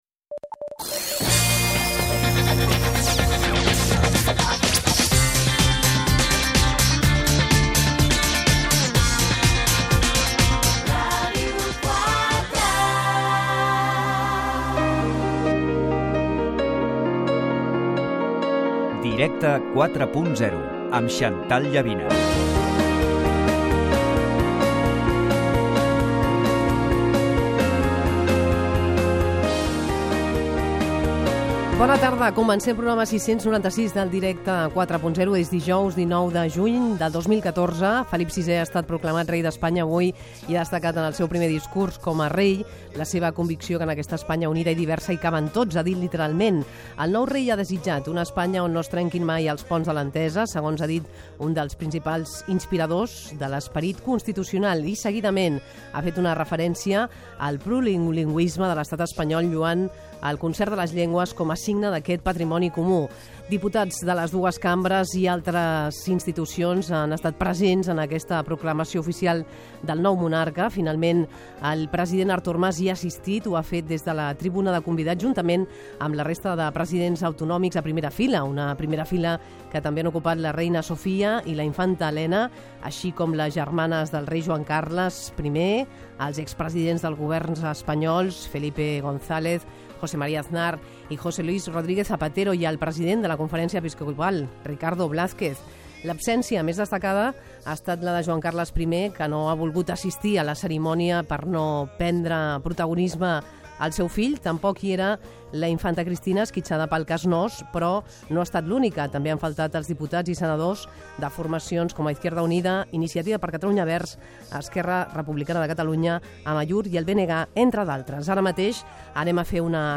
cc79e5a25a9c5645ccbd3bbc509631b1d23d7b6d.mp3 Títol Ràdio 4 Emissora Ràdio 4 Cadena RNE Titularitat Pública estatal Nom programa Directe 4.0 Descripció Indicatius de l'emissora i del programa.